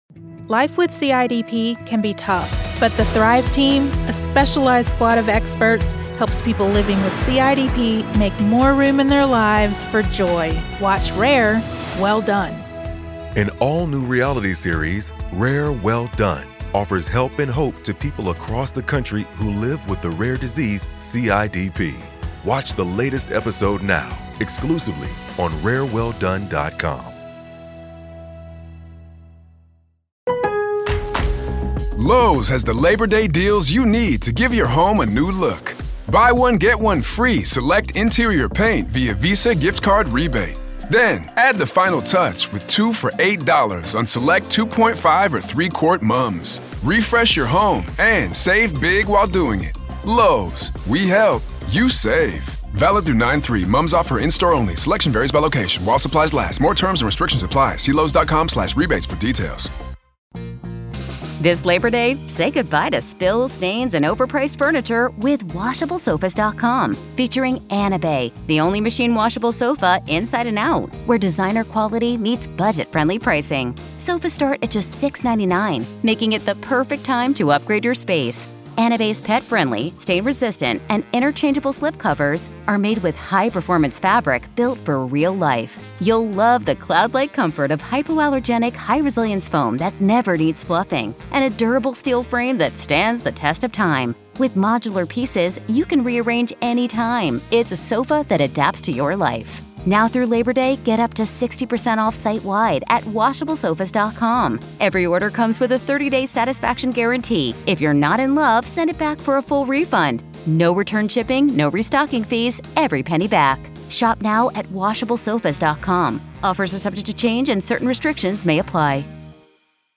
Daily True Crime News & Interviews